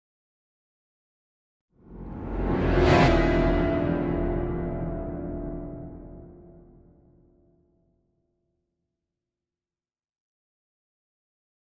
Piano Reversed Danger Chord Type A - Coming, Semitone